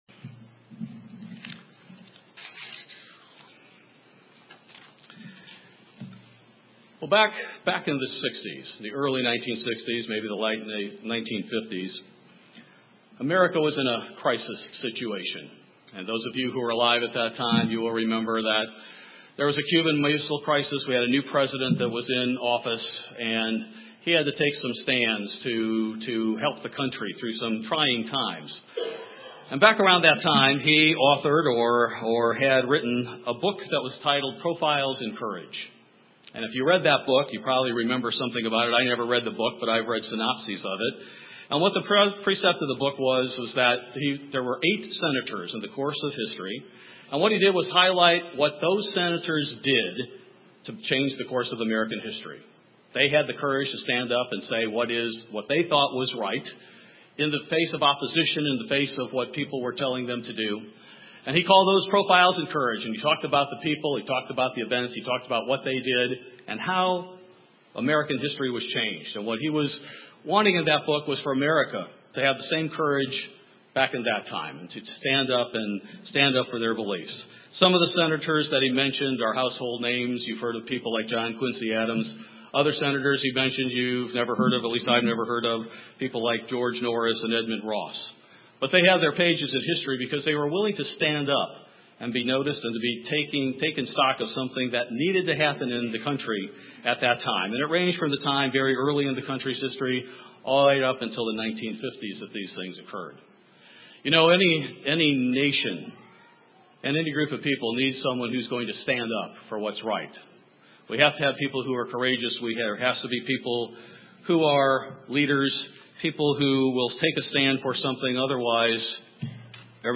In this sermon, we look at the lives of three men in the Bible to develop the "profiles" of great leaders, as God and the Bible define leadership.